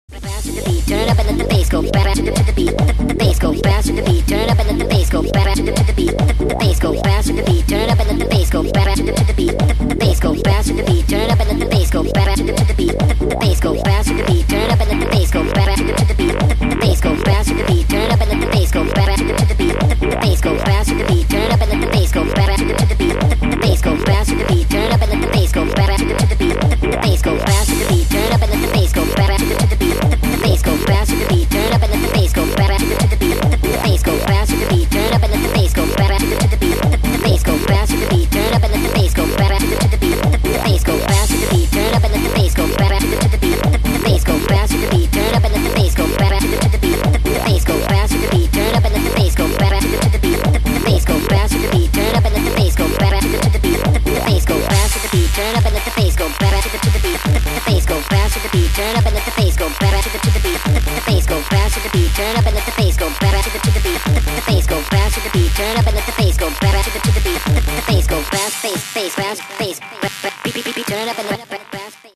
dance
Electronic
club
забавный голос
Trance
Eurodance
смешной голос